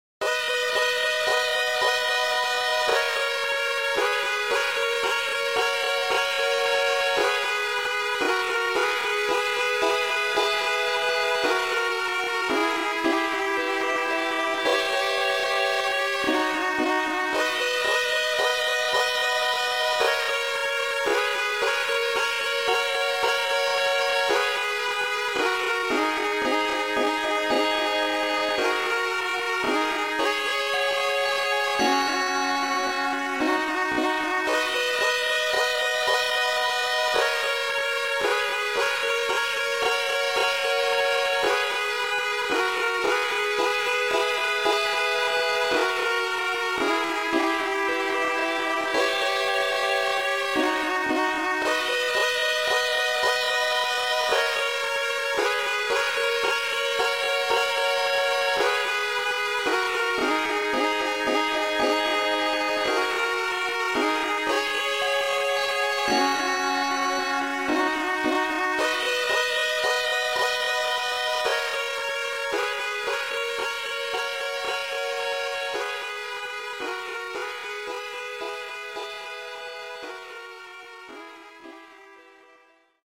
An altered version of the day-to-night transition theme